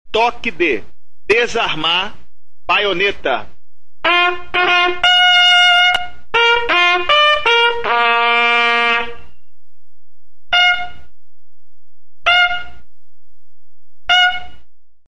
Toques de Corneta
114-toques-de-corneta